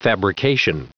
Prononciation du mot fabrication en anglais (fichier audio)
Prononciation du mot : fabrication